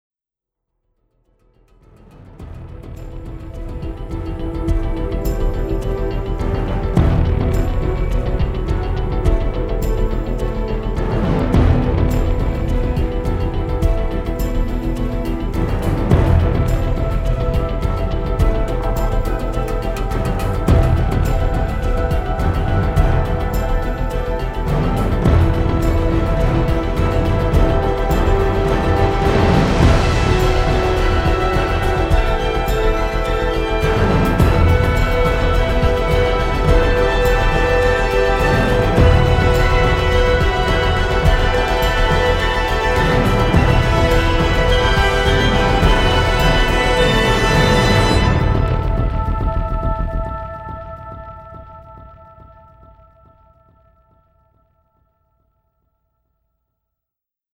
with a tense and modern sheen.